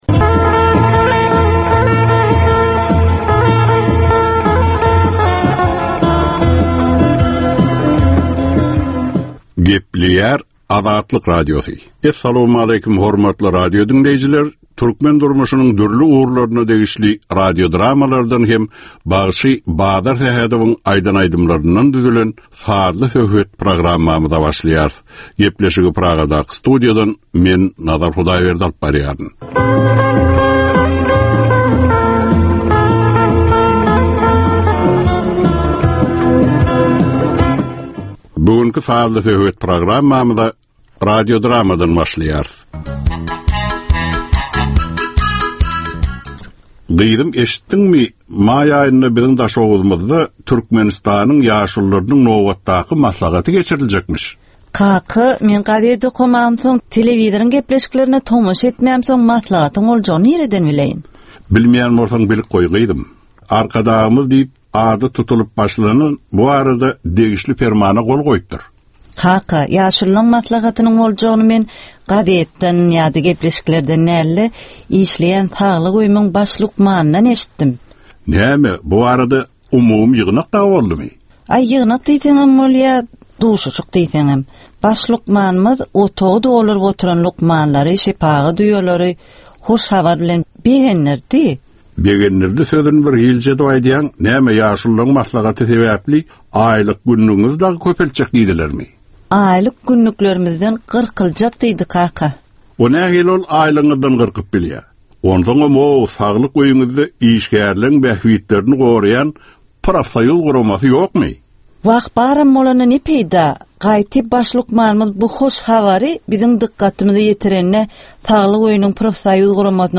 Türkmenistanyň käbir aktual meseleleri barada sazly-informasion programma.